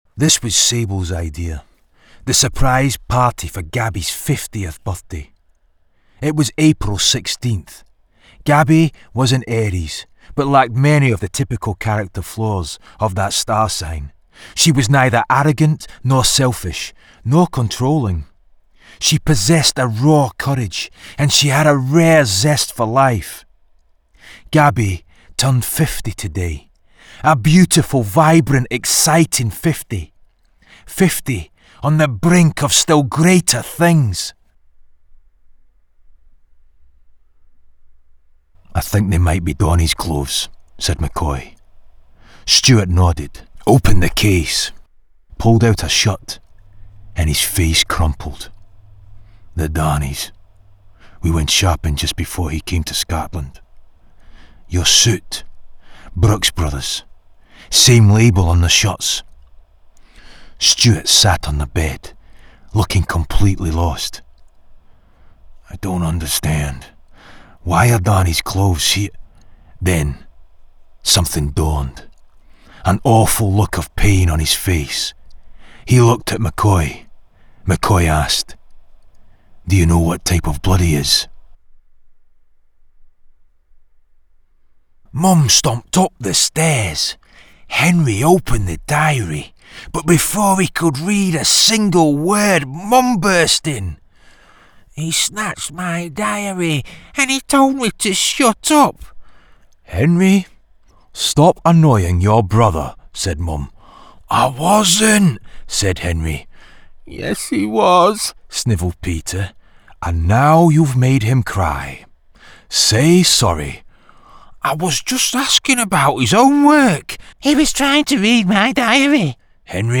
Audiobook Reel
• Native Accent: Glasgow, Yorkshire
Powerful and gritty